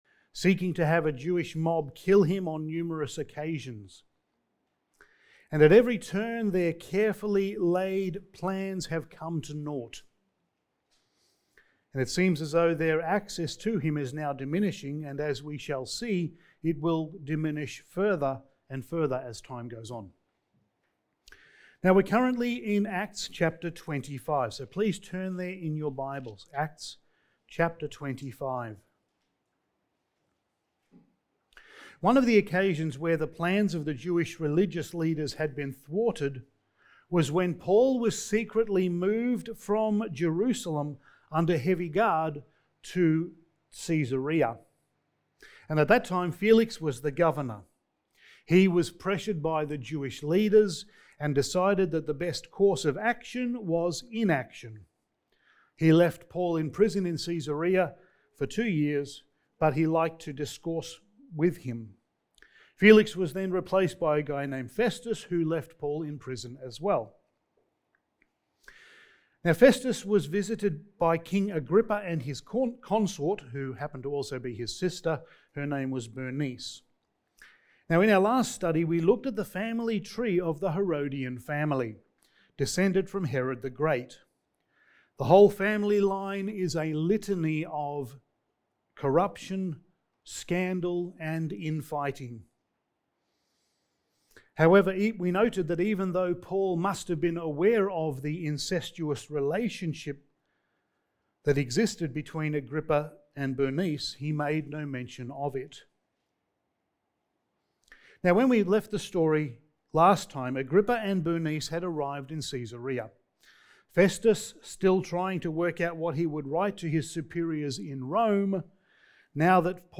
Passage: Acts 25:23-26:28 Service Type: Sunday Morning